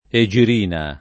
egirina [ e J ir & na ]